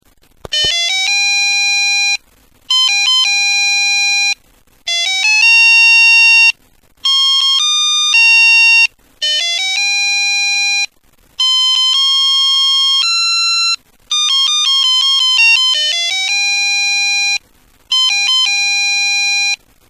ひゃぁ〜、しょぼいのぉ。